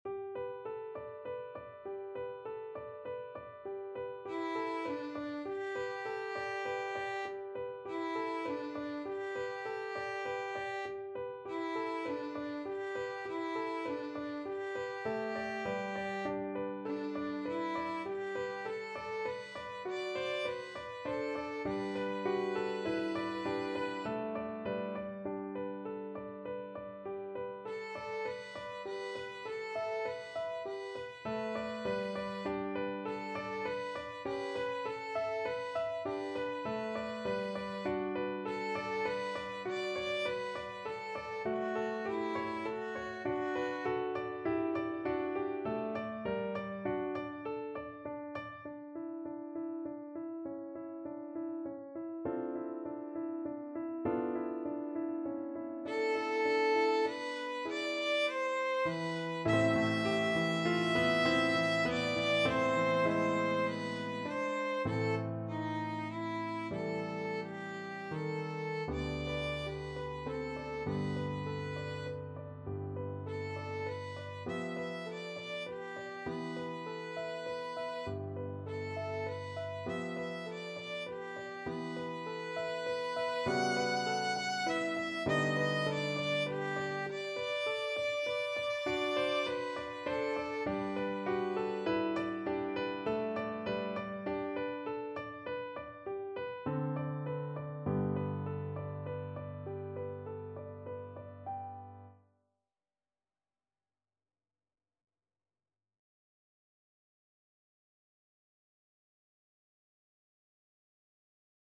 Free Sheet music for Violin
Violin
~ = 120 Allegretto
G major (Sounding Pitch) (View more G major Music for Violin )
Classical (View more Classical Violin Music)
rachmaninoff_op21_5_lilacs_VLN.mp3